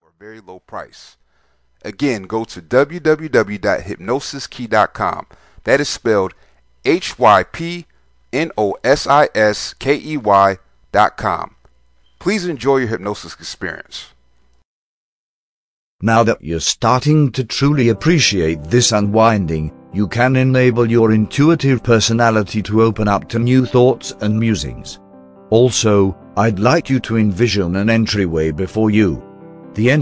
Obsessive Compulsive Disorder Self Hypnosis MP3
Welcome to Compulsive Spending Addiction Hypnosis, this is a powerful hypnosis script that helps you get rid of an Compulsive Spending Addiction. Hypnosis is theorized to work by altering your state of mind, it does this in such a way that the left brain is turned off, while the non-analytical right side is made alert.